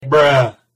Memes
Bruh Sound Effect